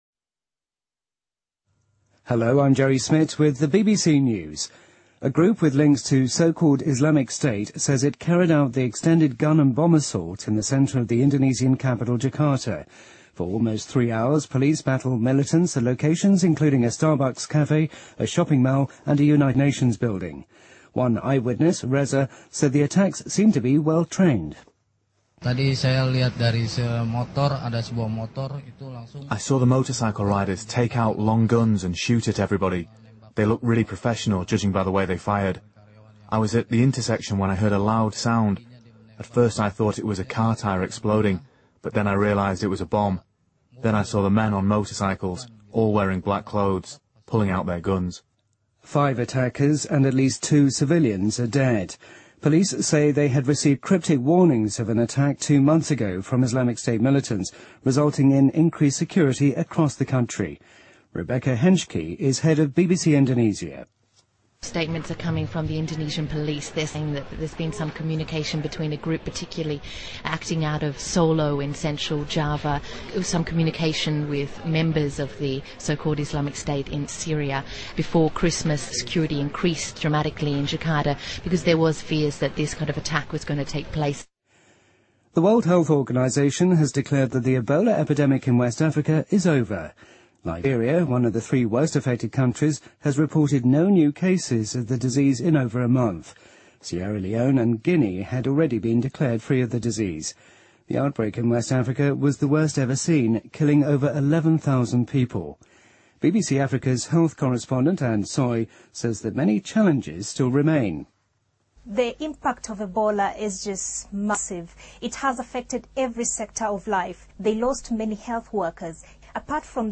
BBC news,世卫组织宣告西非的埃博拉疫情结束
日期:2016-01-16来源:BBC新闻听力 编辑:给力英语BBC频道